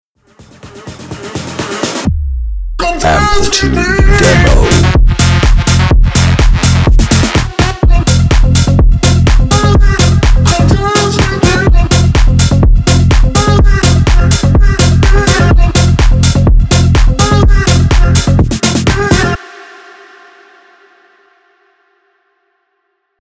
125 BPM  F Major  7B
Tech House